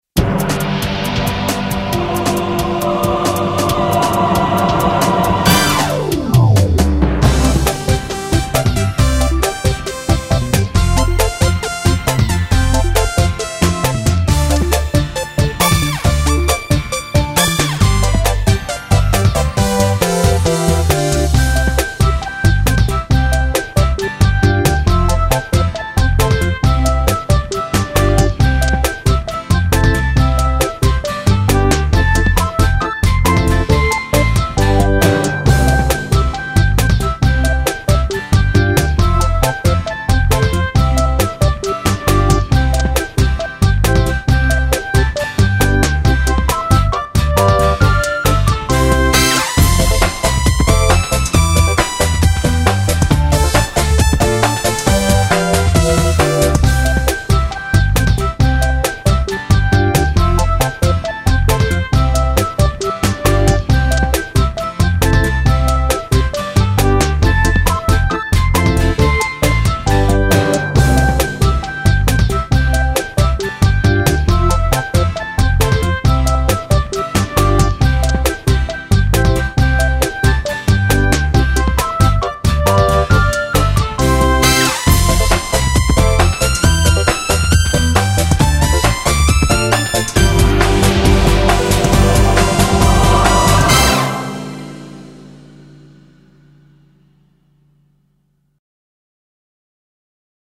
老鼠(純伴奏版) | 新北市客家文化典藏資料庫